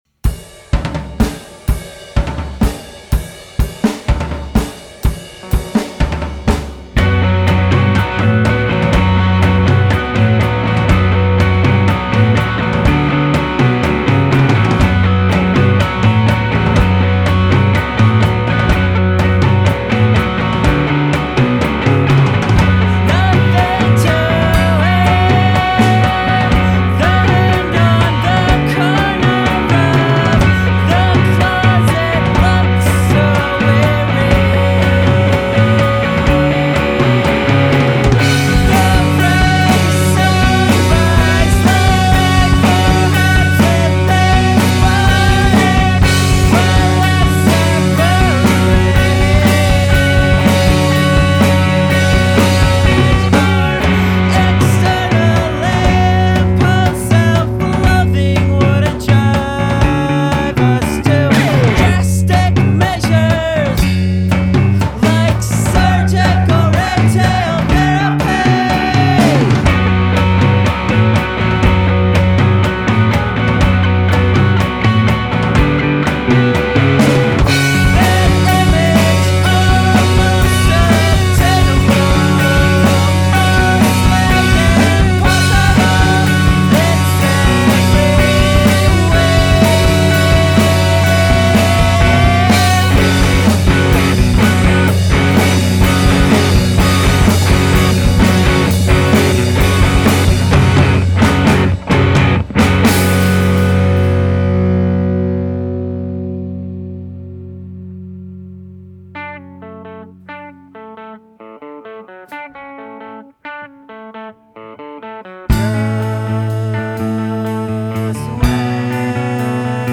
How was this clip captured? recorded at bear creek studio